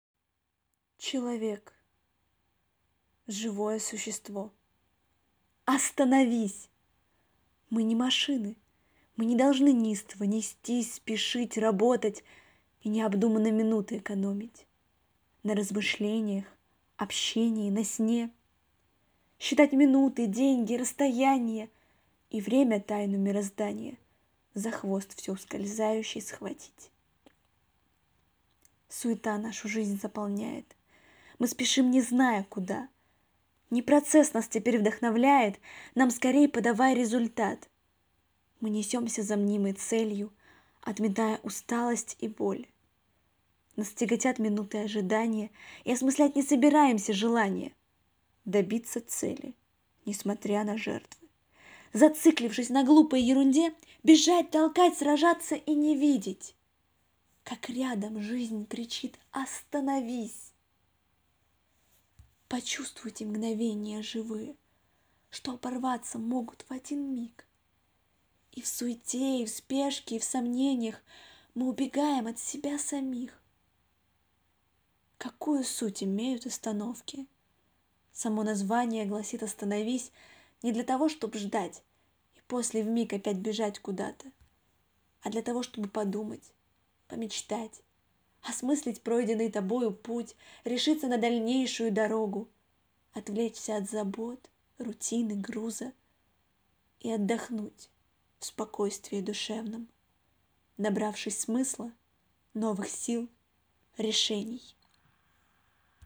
Стихотворение Остановка.mp3